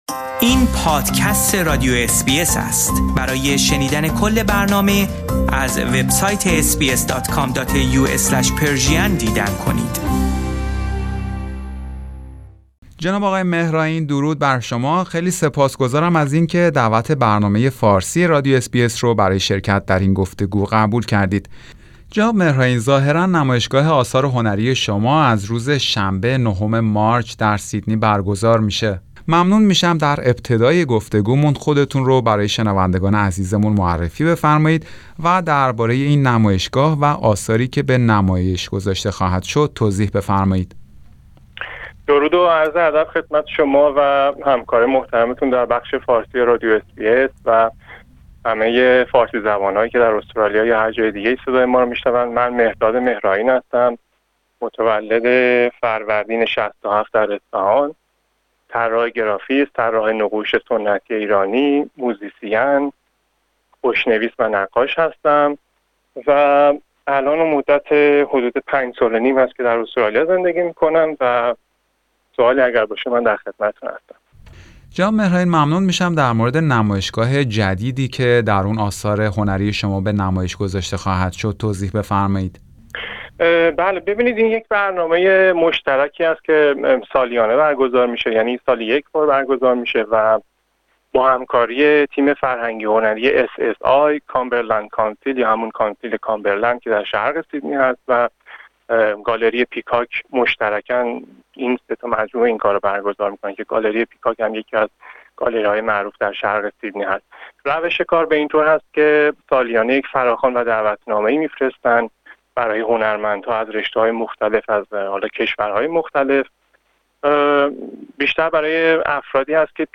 در گفتگو با برنامه فارسی رادیو اس بی اس در مورد این نمایشگاه و ادامه فعالیت خود در استرالیا توضیح داده است.